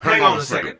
H315VOCAL.wav